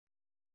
♪ mēkudōṛ'